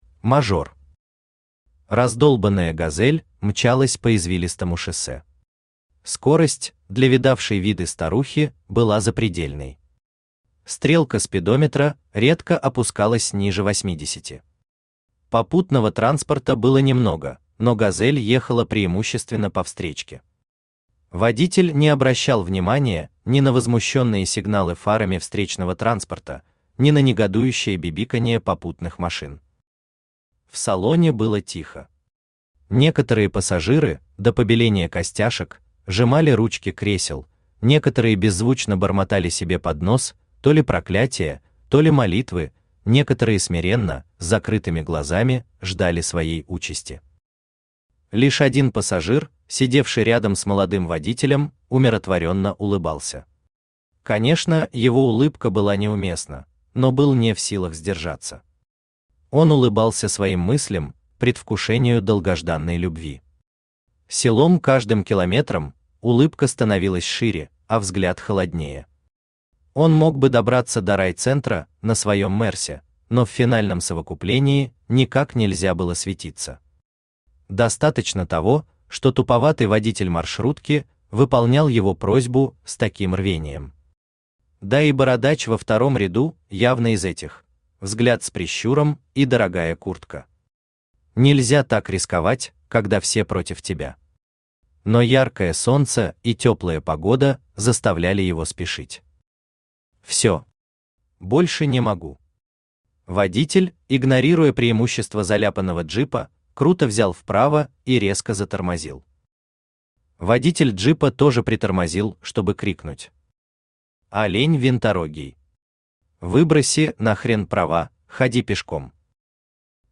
Аудиокнига Мажор | Библиотека аудиокниг
Aудиокнига Мажор Автор ШаМаШ БраМиН Читает аудиокнигу Авточтец ЛитРес.